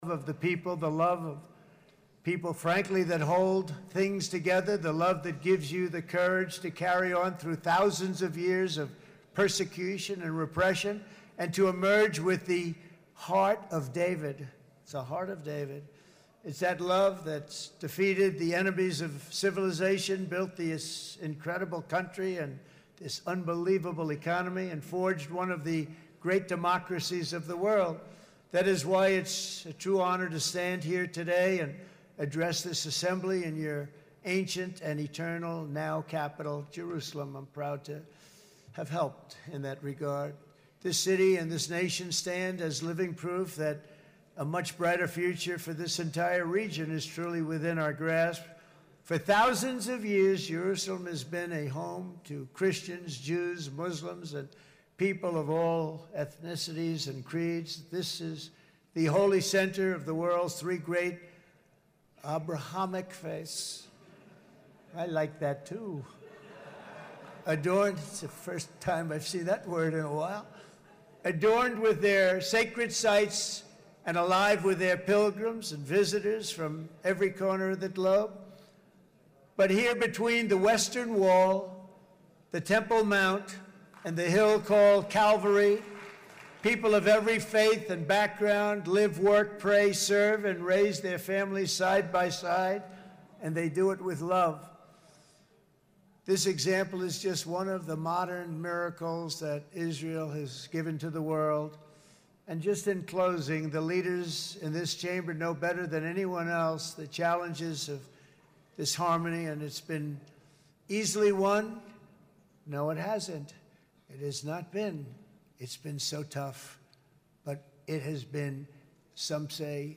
Trump Speech Live, UWF Football update, US Congressman Jimmy Patronis